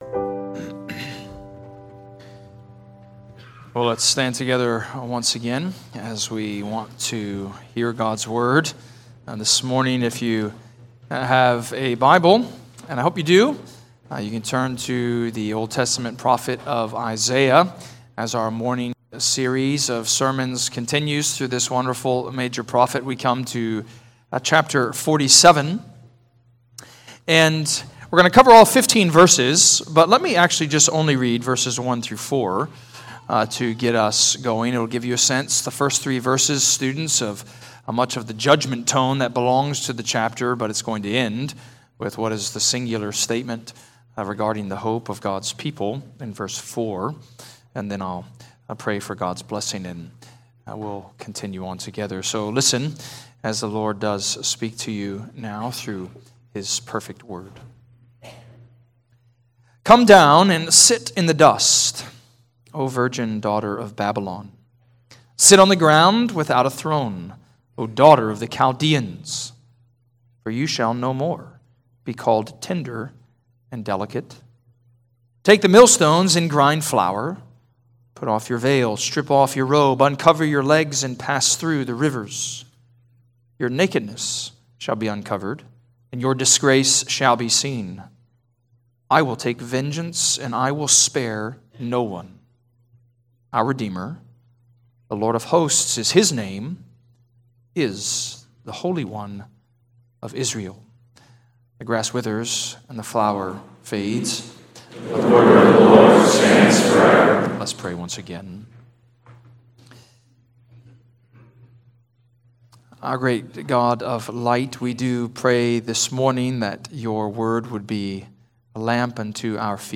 preached on Isaiah 47.